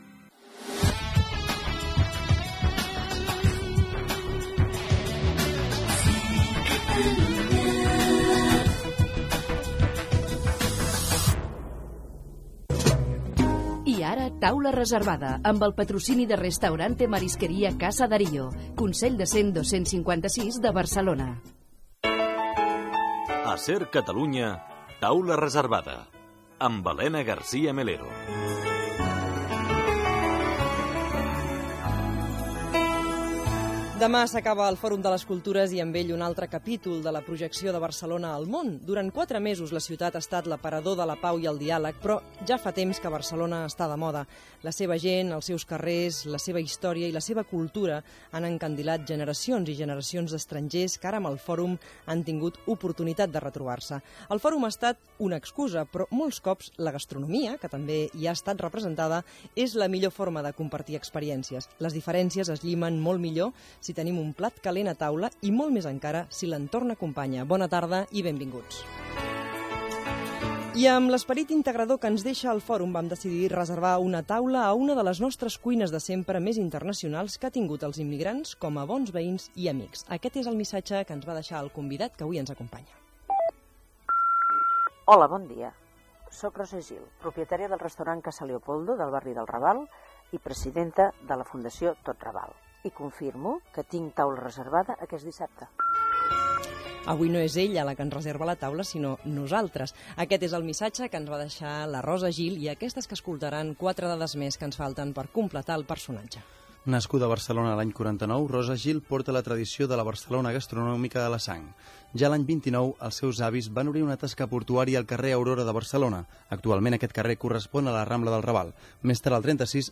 Indicatiu, careta del programa, presentació i entrevista